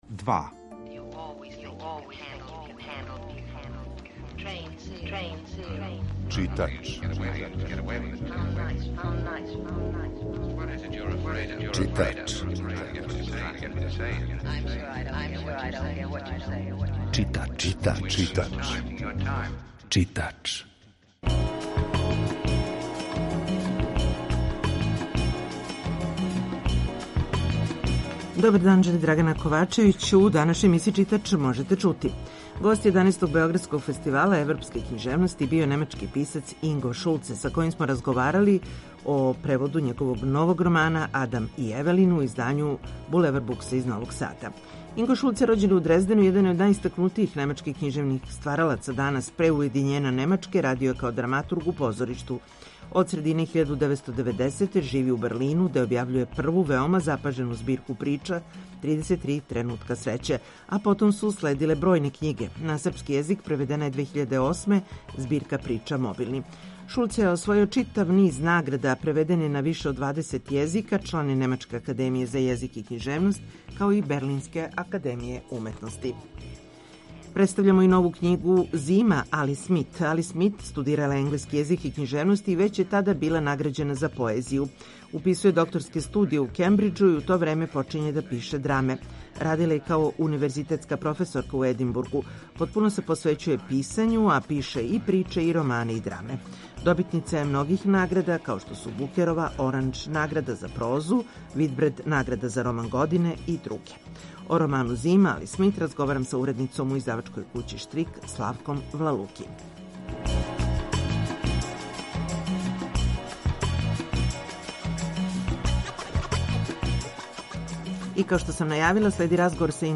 Гост 11. Београдског фестивала европске књижевности био је немачки писац Инго Шулце (1962), са којим смо разговарали и о новом преводу његовог романа „Адам и Евелин".
Извор: Радио Београд 2